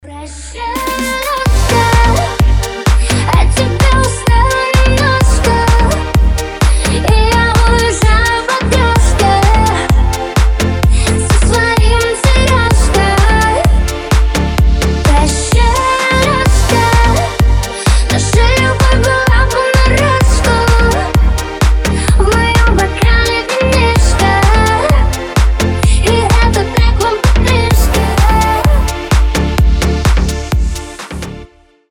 Club House
ремиксы